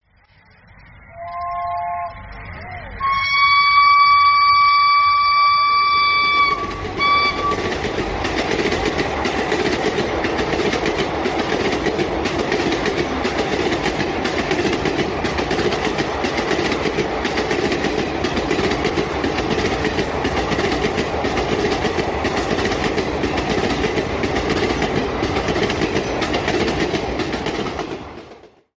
เสียงรถไฟไอน้ำ
เสียงเรียกเข้าโทรศัพท์เพราะๆ เสียงนกหวีดที่ดังเพราะที่สุดในโลก
หมวดหมู่: เสียงการจราจร
คำอธิบาย: ดาวน์โหลด MP3 เสียงหวีดของคุณทวด, รถไฟไทย, สถานีรถไฟศาลายา, ขบวนที่ 908, หัวรถจักรไอน้ำ, รถจักรไอน้ำแบบแปซิฟิก